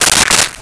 Stapler 02
Stapler 02.wav